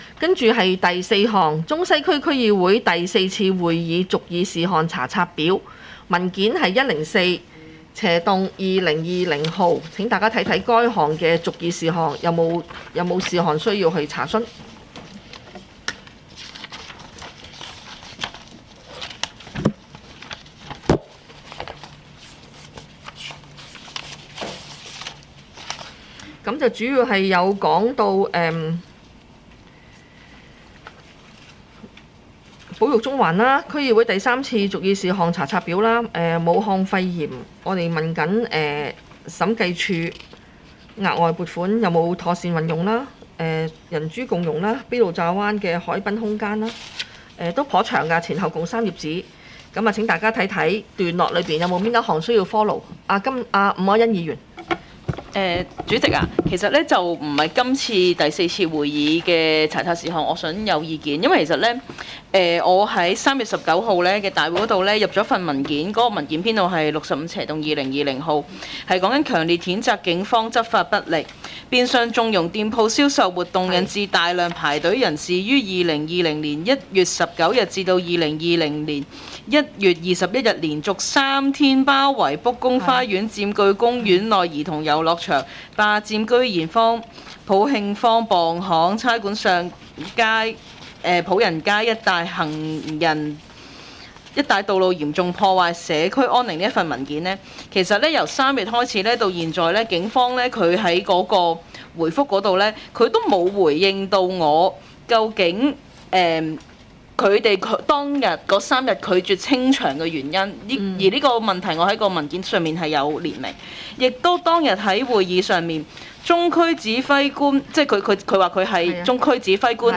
区议会大会的录音记录
中西区区议会会议室